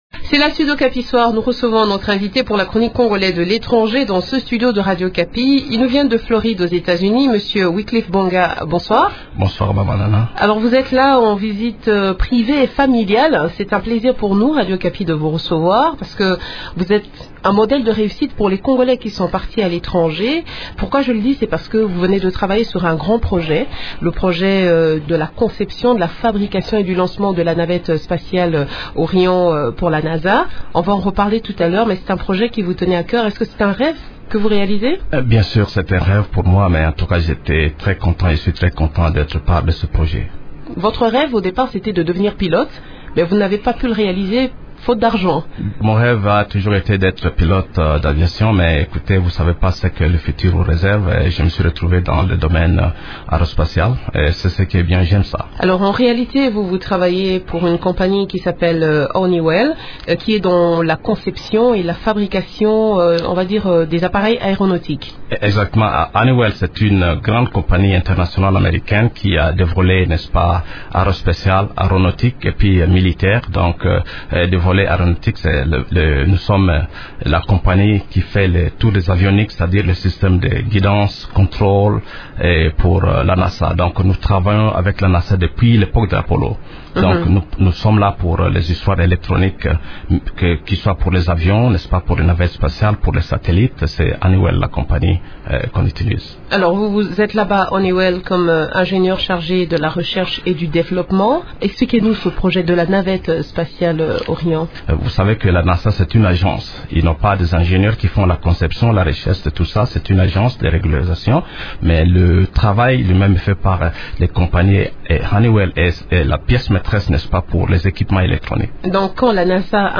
Il nous parle de cette incroyable aventure dans cet entretien